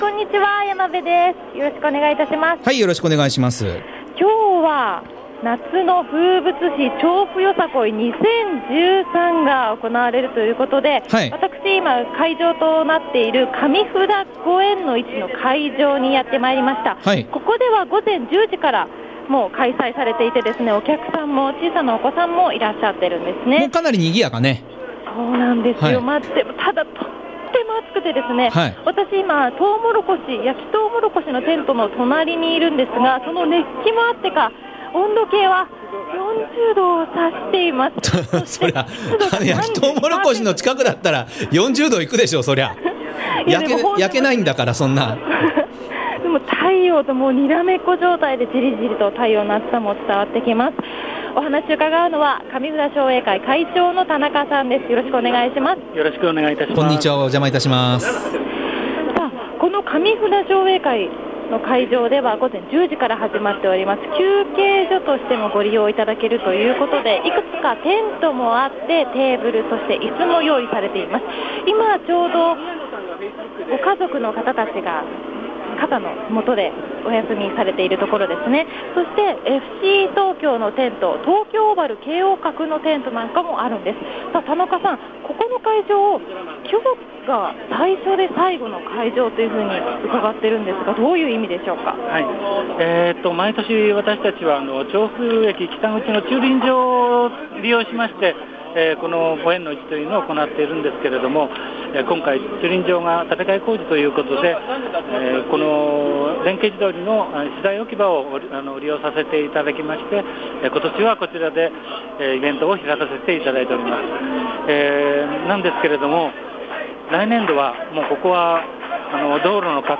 今日は調布の夏の風物詩、調布よさこい２０１３の会場の１つ、上布田ご縁の市会場へお邪魔しました。